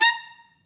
DCClar_stac_A#4_v3_rr2_sum.wav